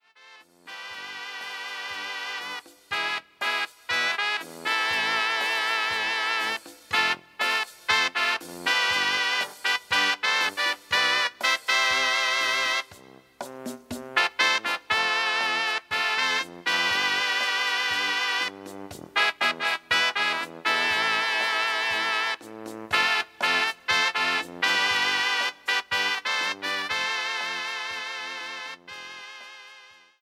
97-Banda-6.mp3